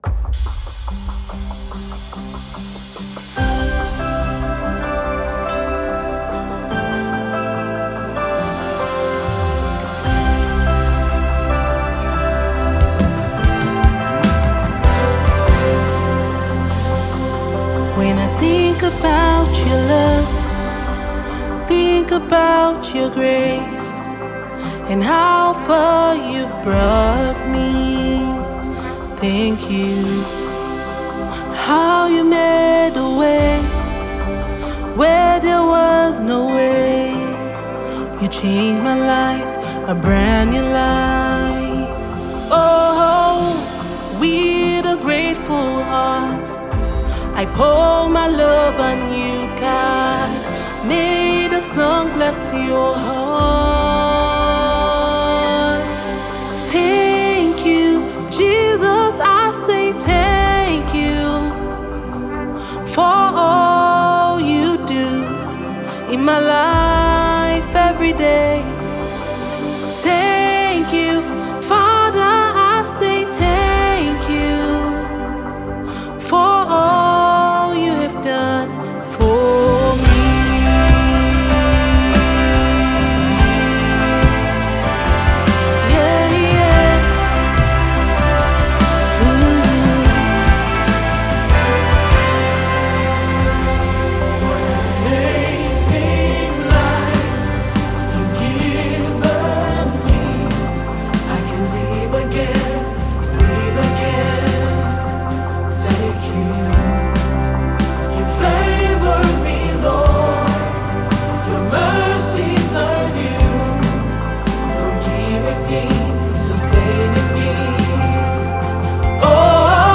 Versatile Nigerian gospel minister